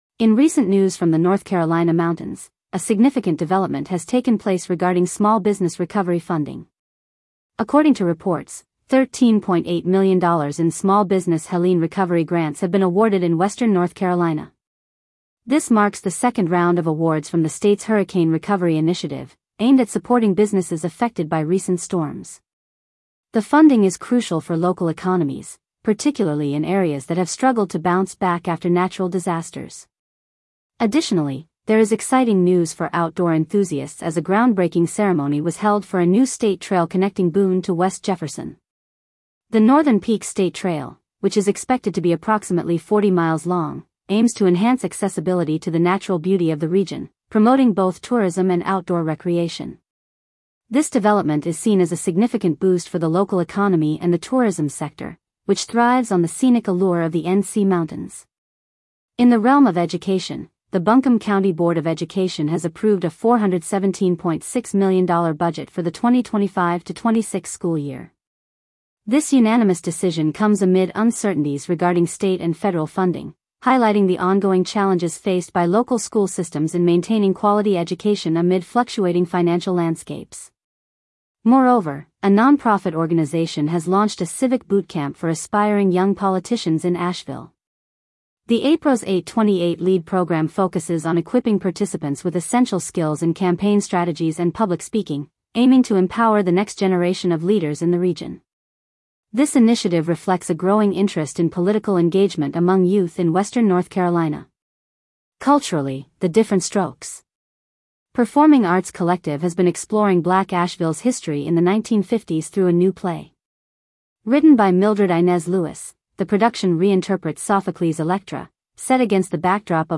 NC Mountains Summary - Daily Audio News Transcript
North Carolina News